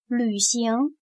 旅行/Lǚxíng/Viajar, recorrer, estar de gira.